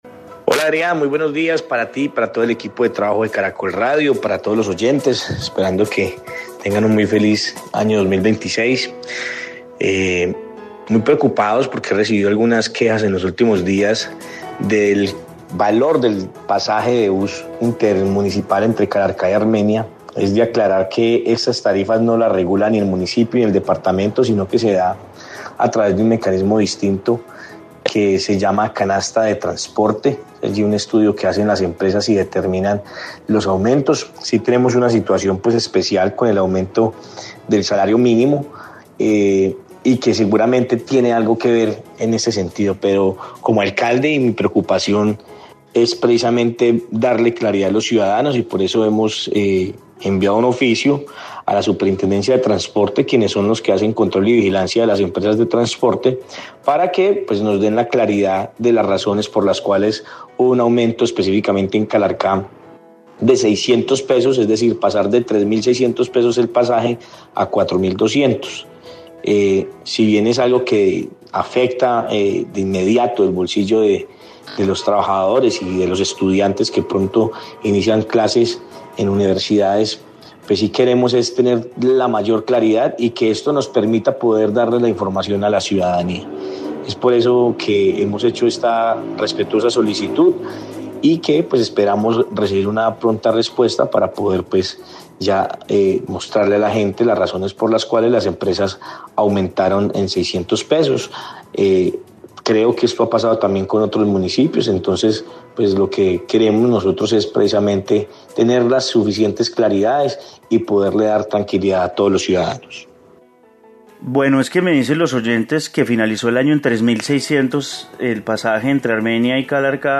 Informe pasaje en bus Armenia - Calarcá
En 6AM/W de Caracol Radio hablamos con el alcalde de Calarcá Sebastián Ramos que a través de redes sociales se manifestó frente al incremento en la tarifa del bus intermunicipal desde este mes de enero y señaló “muy preocupados porque he recibido algunas quejas en los últimos días del valor del pasaje de bus intermunicipal entre Calarcá, Armenia, es de aclarar que esas tarifas no las regula ni el municipio ni el departamento, sino que se da a través de un mecanismo distinto que se llama canasta de transporte allí un estudio que hacen las empresas y determinan los aumentos.
Conductor de bus: